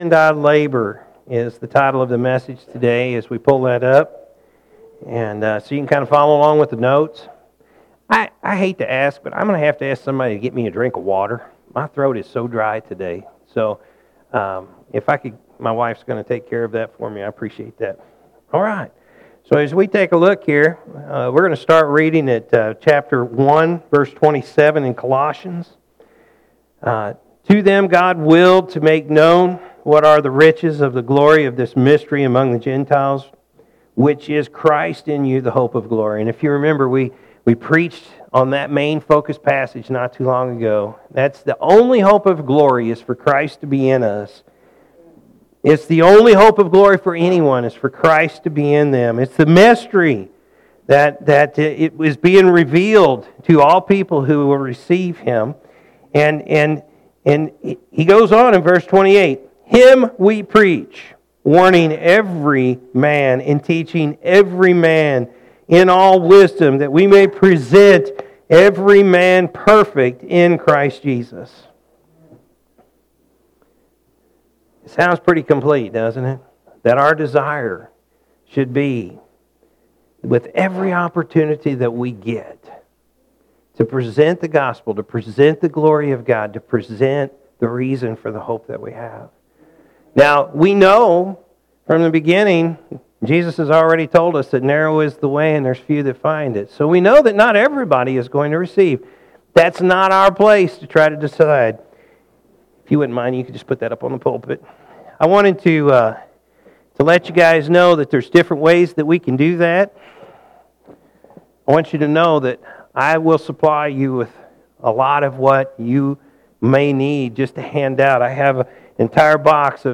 Jan.-5-2020-Morning-Service.mp3